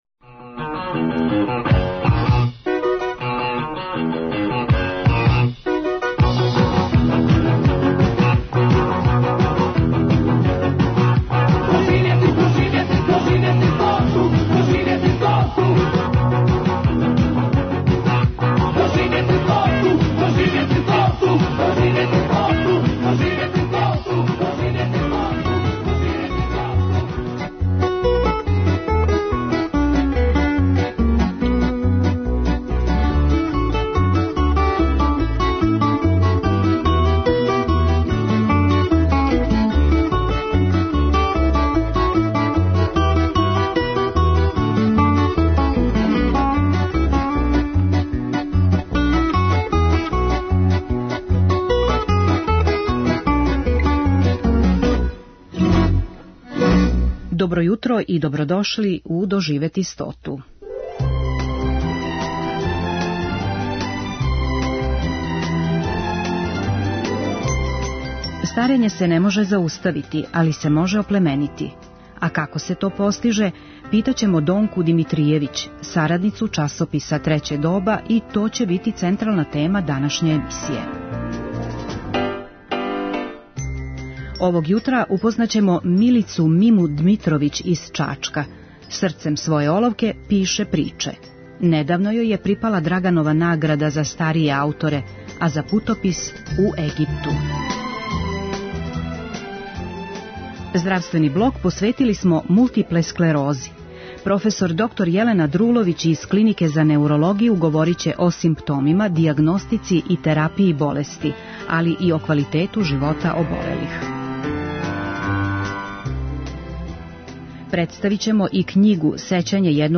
Емисија "Доживети стоту" Првог програма Радио Београда доноси интервјуе и репортаже посвећене старијој популацији.